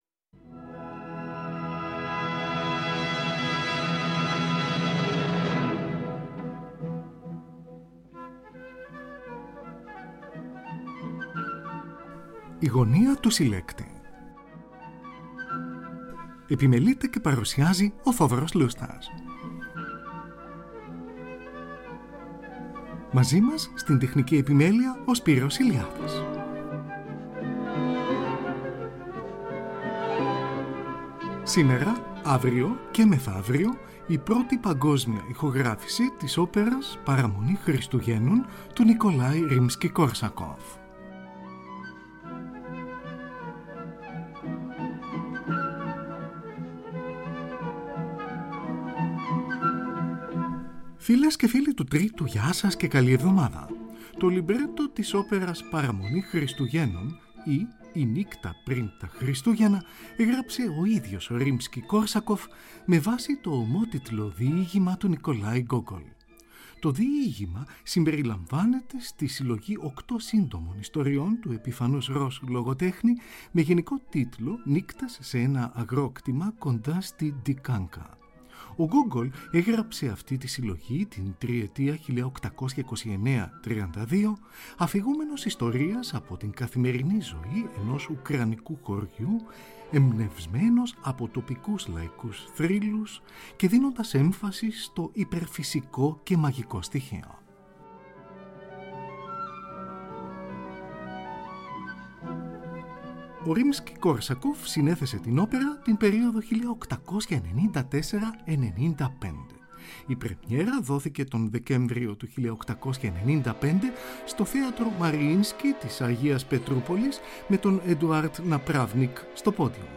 ο τενόρος
η μεσόφωνος
η υψίφωνος
Πρώτη παγκόσμια ηχογράφηση .